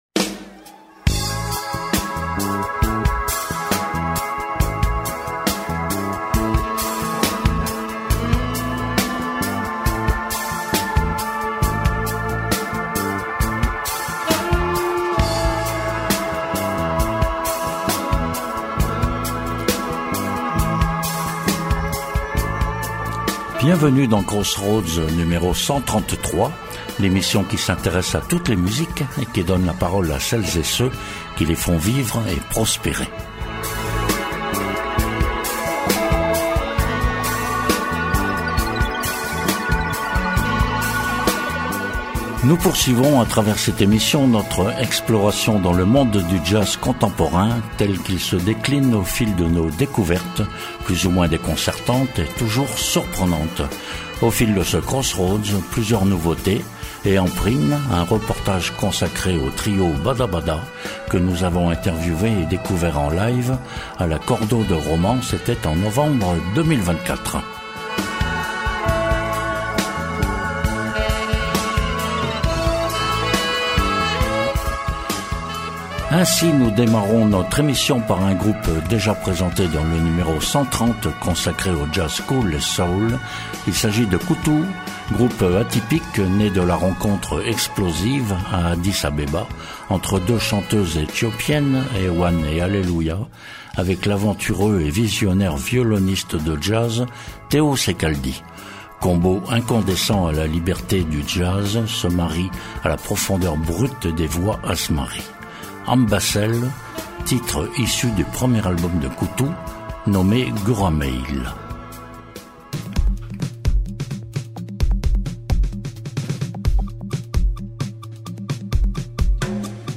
Le n°133 de Crossroads nous donne l’occasion d’explorer encore un peu plus les nouveaux territoires empruntés par les artistes jazz contemporains.
A l’affiche, une majorité de trios francophones, pour la plupart découverts au Jazz Action Valence ou à la Cordo de Romans.